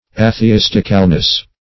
A`the*is"tic*al*ly, adv. -- A`the*is"tic*al*ness, n.